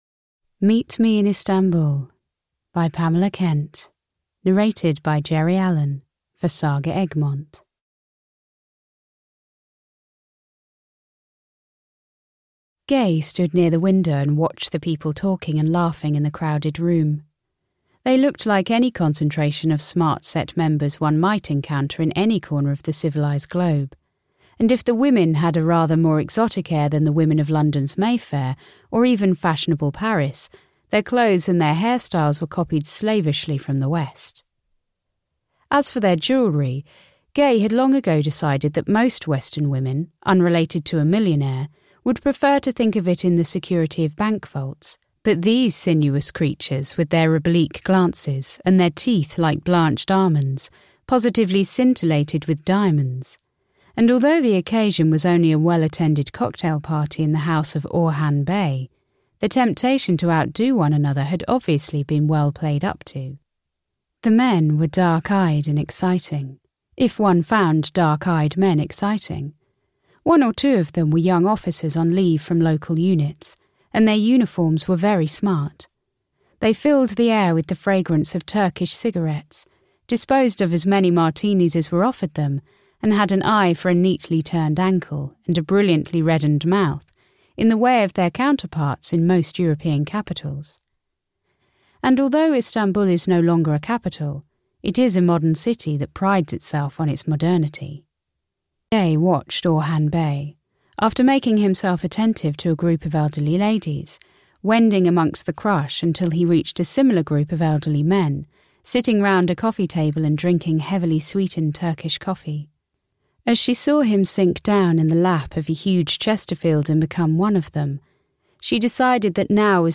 Meet me in Istanbul (EN) audiokniha
Ukázka z knihy